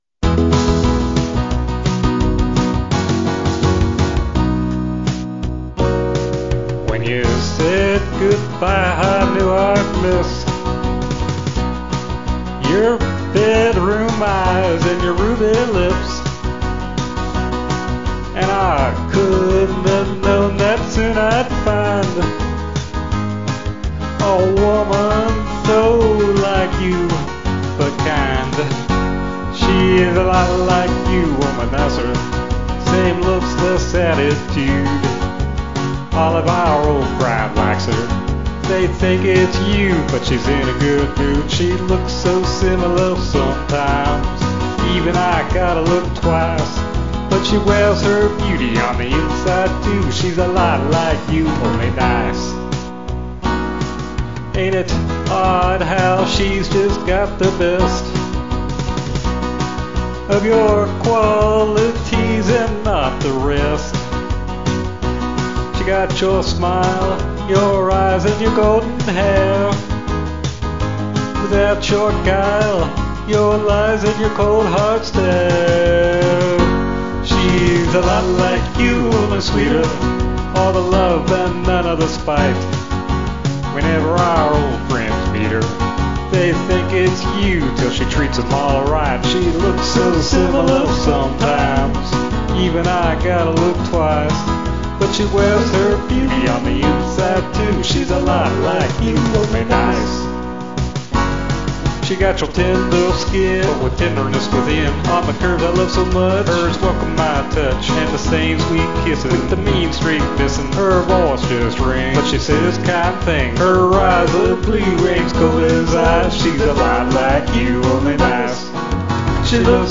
uptempo country, male voice